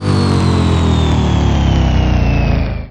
apowerdown.wav